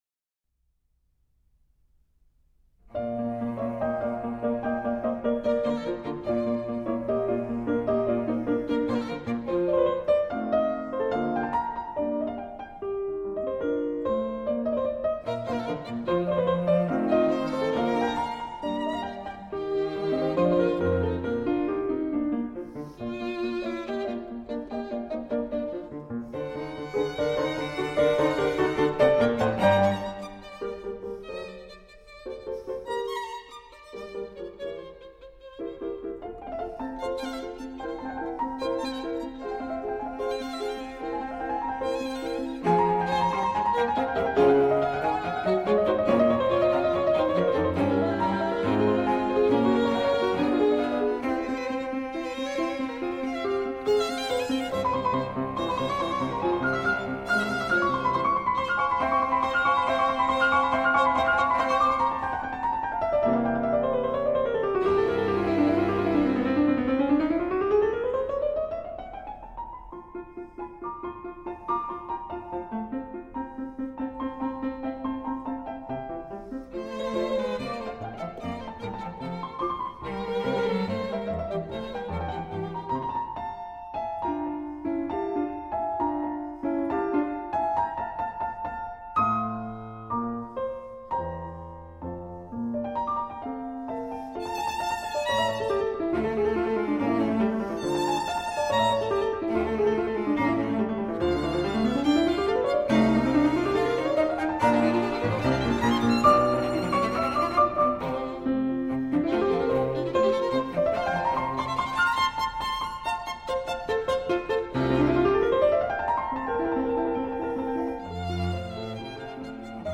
A group of three musicians.
Piano Trio in B flat